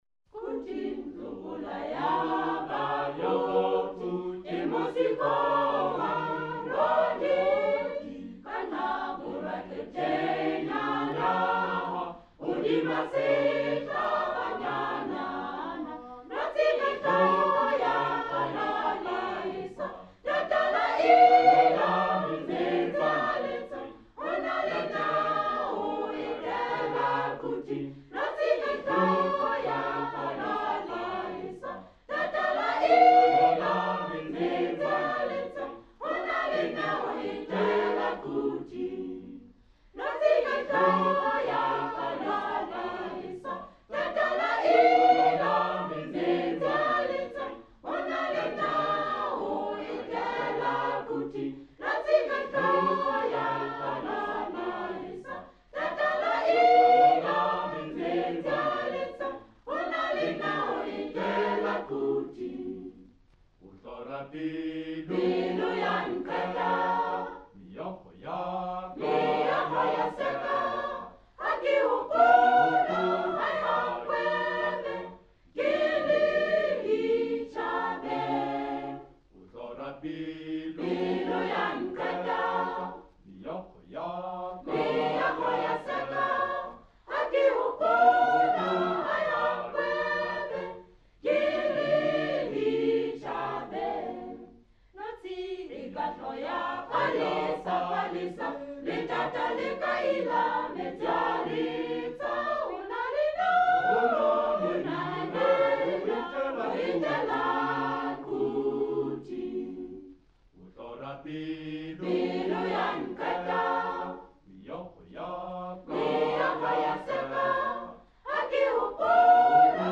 choir SATB